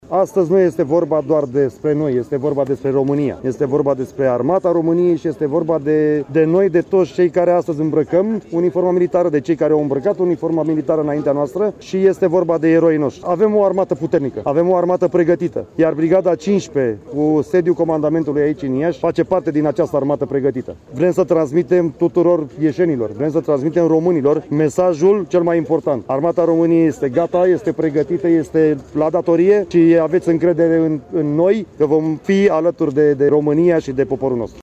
La Iași, Ziua Armatei a fost marcată printr-un ceremonial militar și religios.
Manifestările s-au desfășurat la comandamentul Brigăzii 15 Mecanizată Podu Înalt de la Iași.